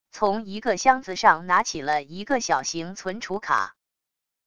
从一个箱子上拿起了一个小型存储卡wav音频